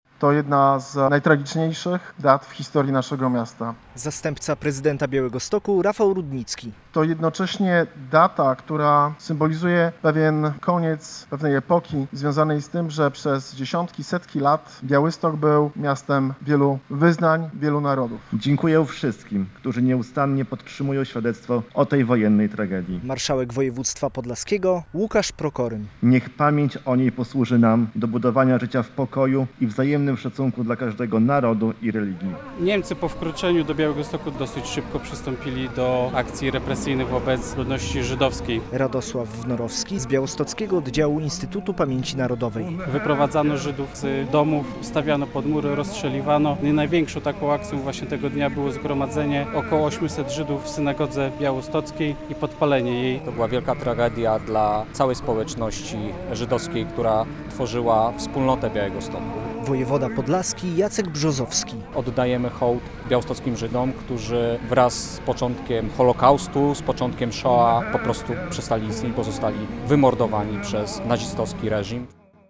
W południe przy pomniku upamiętniającym tamte wydarzenia - przedstawiającym spaloną kopułę - który stoi w miejscu dawnej Wielkiej Synagogi, kwiaty złożyli przedstawiciele władz państwowych i samorządowych, organizacji społecznych upamiętniających Żydów, służby mundurowe.